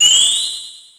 snd_spellcast_ch1.wav